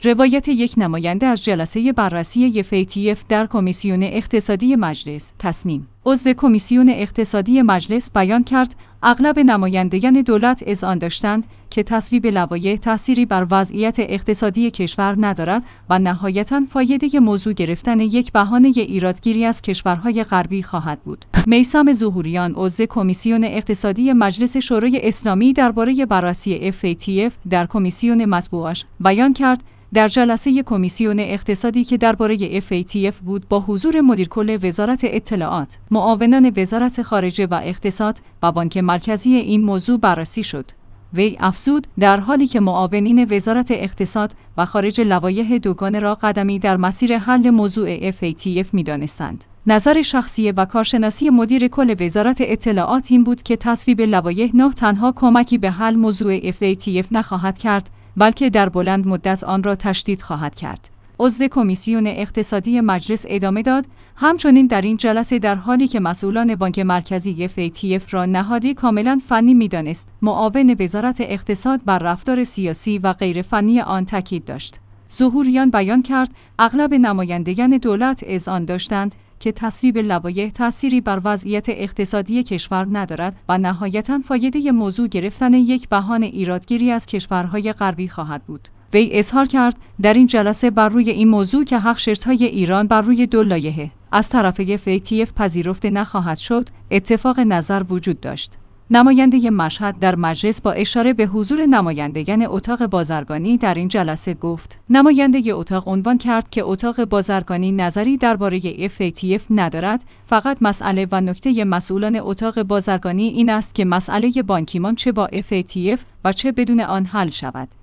روایت یک نماینده از جلسه بررسی «FATF» در کمیسیون اقتصادی مجلس